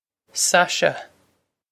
Sasha
This is an approximate phonetic pronunciation of the phrase.